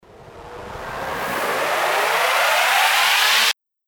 Genre: Blues.
FX-1456-RISER